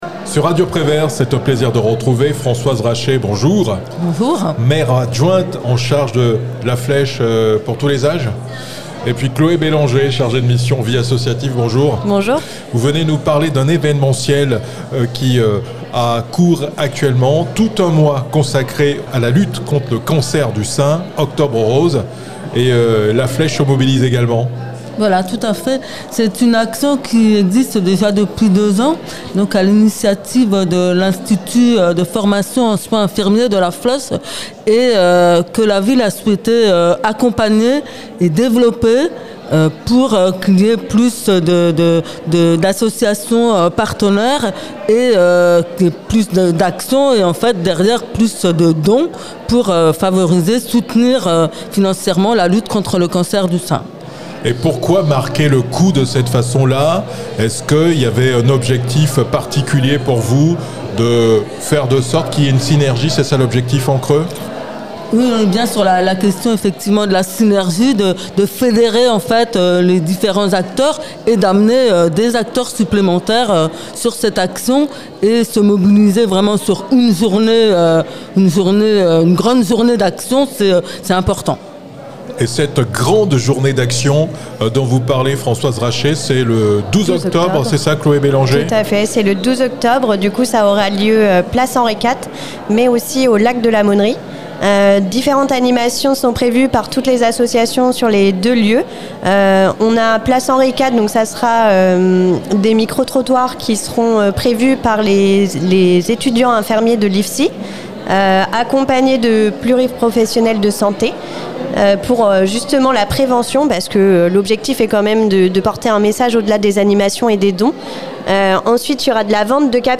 Françoise Rachet, maire adjointe en charge de La Flèche pour tous les âges